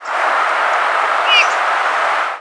Orchard Oriole Icterus spurius
Flight call description A descending, burry "yeehr".  In short flights also gives a low, husky "chhk", often doubled, and rarely a descending, whistled "heoo".